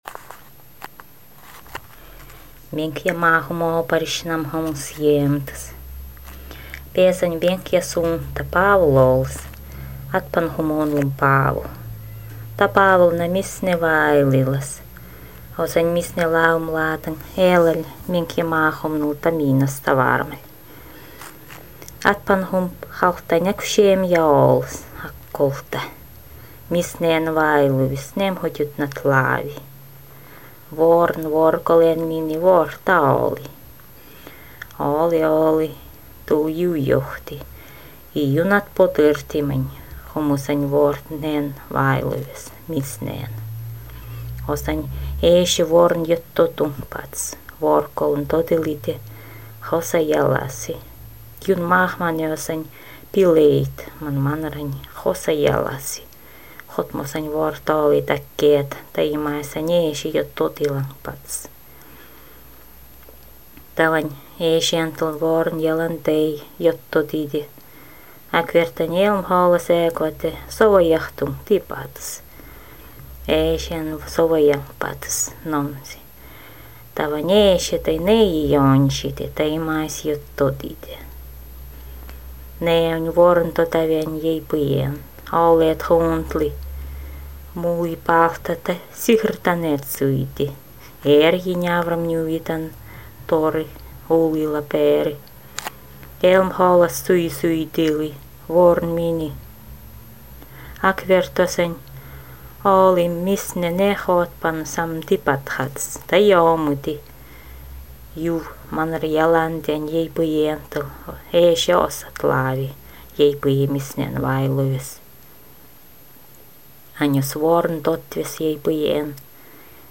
Мансийская легенда о происхождении фамилии (рода) Алгадьевых | Обско-угорский институт прикладных исследований и разработок
Место записи : г. Ханты-Мансийск